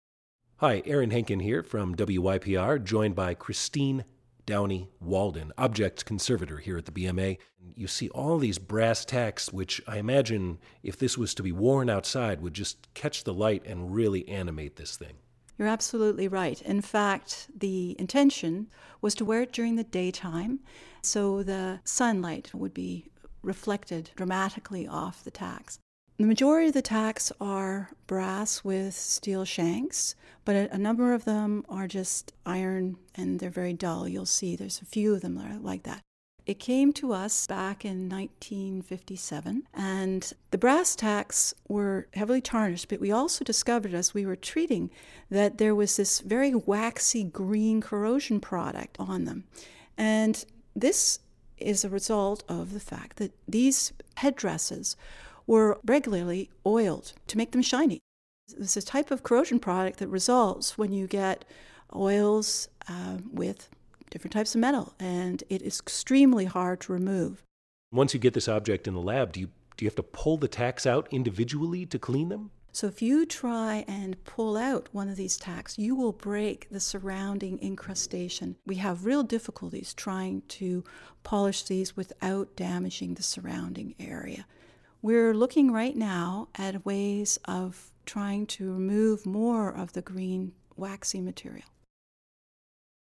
A Conversation About the Tacks